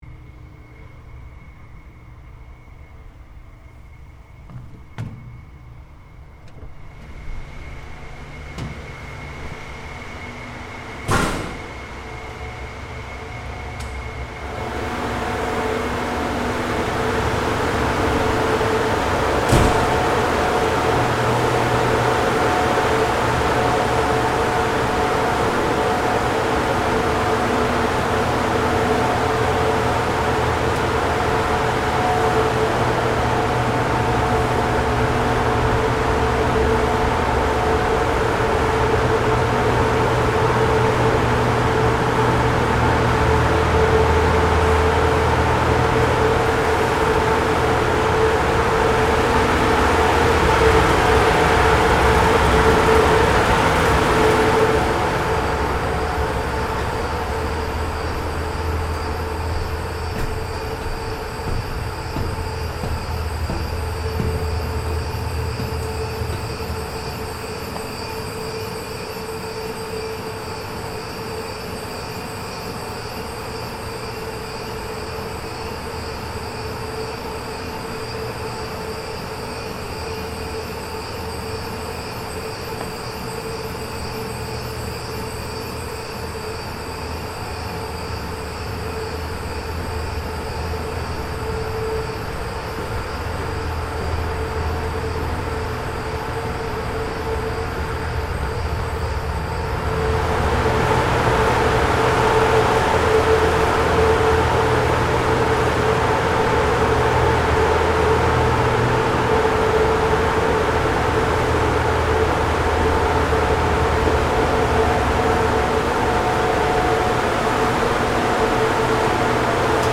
Soundscape: Paranal VISTA tour
(Exterior-interior) Welcome to the Visible and Infrared Survey Telescope for Astronomy (VISTA), housed at Paranal Observatory, on the peak 1500 m away from the main one hosting the VLT. This audio tour walks you through the main door of the telescope, upstairs to the 4.1 meter main mirror and around it, to finally exit the premises.
ss-paranal-vista-tour_stereo.mp3